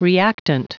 Prononciation du mot reactant en anglais (fichier audio)
Prononciation du mot : reactant